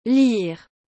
Como se escreve e pronuncia “ler” em francês?
A pronúncia? É /liʁ/, com aquele “r” francês charmoso no final.
• O “i” soa como em “fino”.
• O “r” final tem aquele som gutural suave típico do francês.